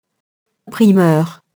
primeur [primɶr]